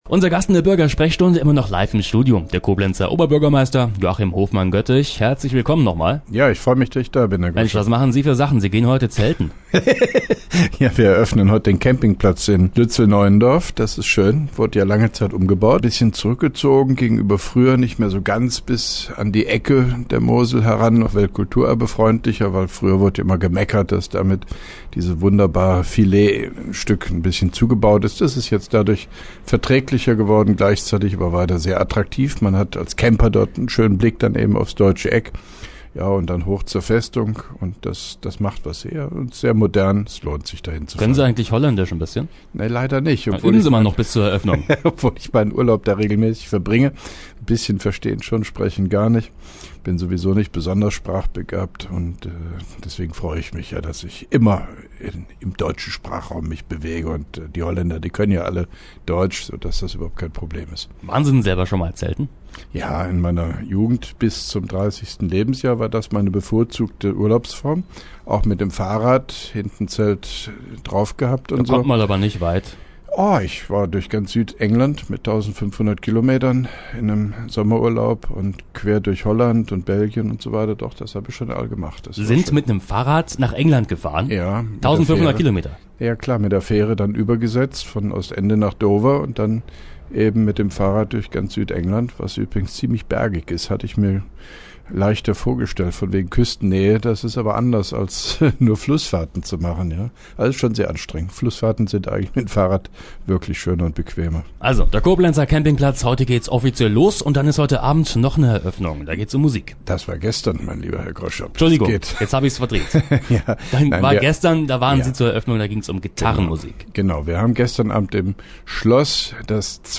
(3) Koblenzer Radio-Bürgersprechstunde mit OB Hofmann-Göttig 22.05.2012
Antenne Koblenz 98,0 am 22.05.2012, ca. 8.50 Uhr, (Dauer 03:05 Minuten)